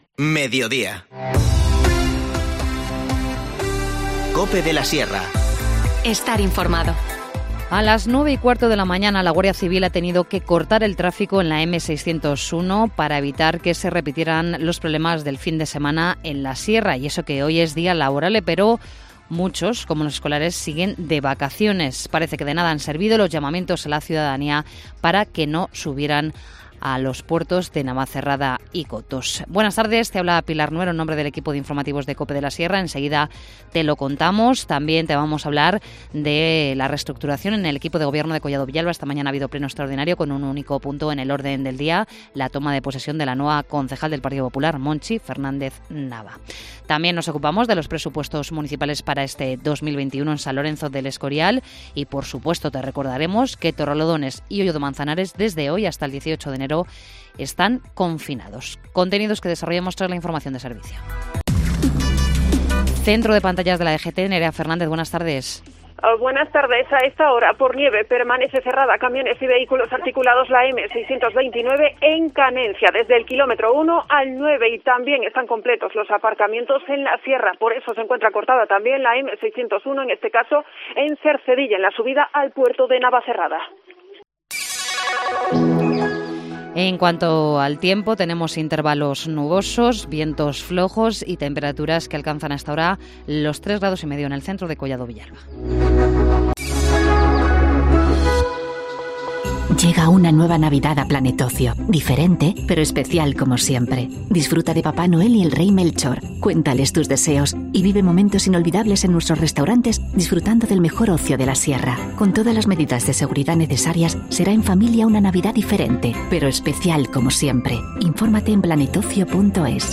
Informativo Mediodía 4 enero